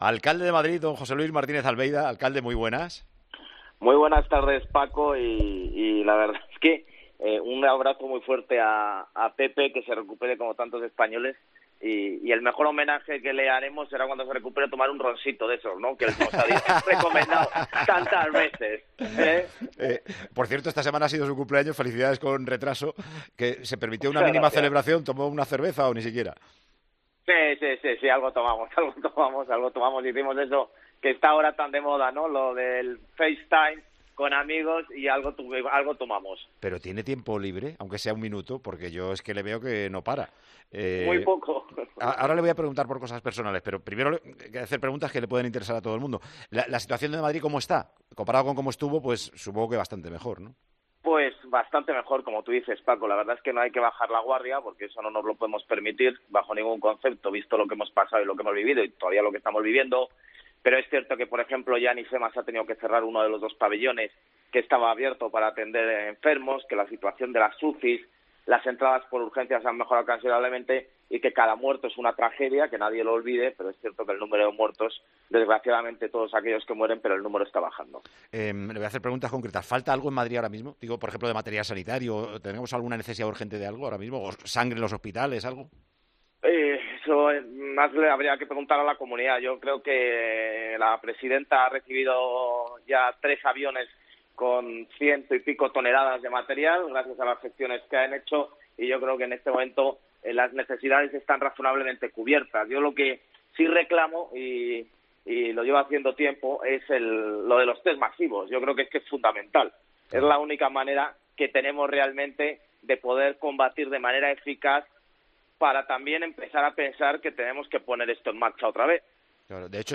AUDIO: El alcalde de Madrid confiesa en Tiempo de Juego que uno de sus peores momentos fue visitar por primera vez la morgue del Palacio de Hielo.